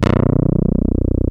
ODSSY BS 3.wav